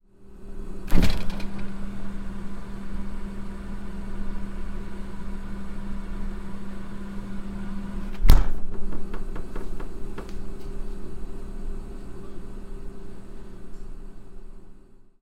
描述：门开启和关闭几次的声音